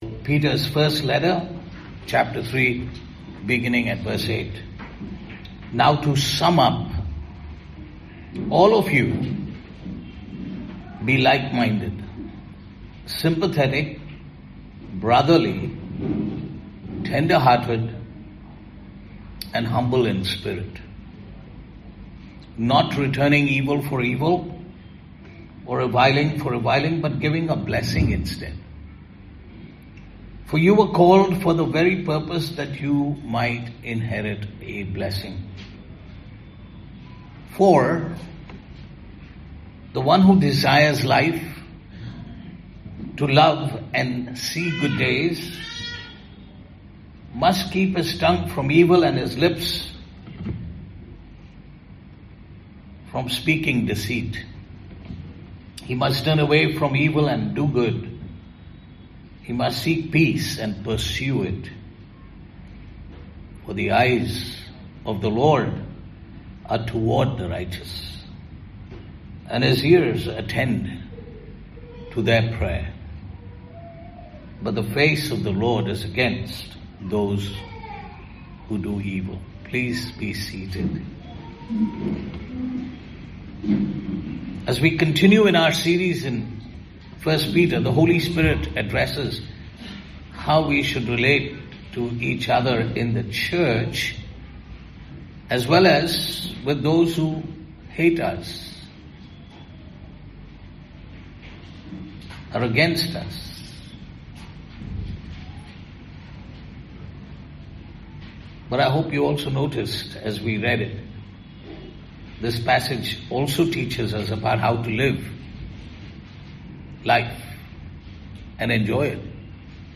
Passage: 1 Peter 3:8-12 Service Type: Sunday Morning « Both Wife And Husband Must Be Godly God’s Way to a Happy Church & Personal Life
Sermon-2.mp3